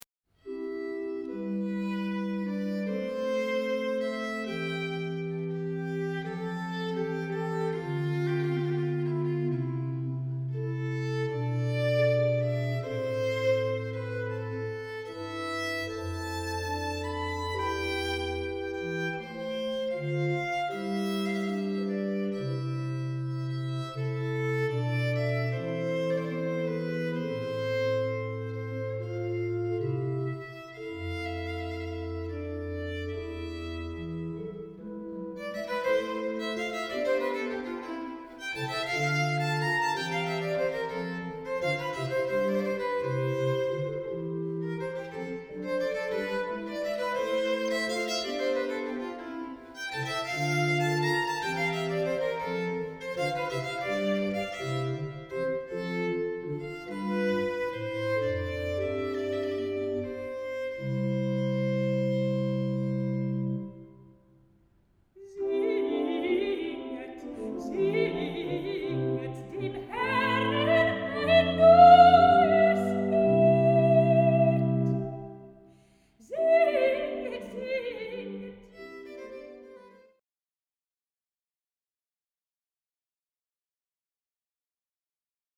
viulu ja bc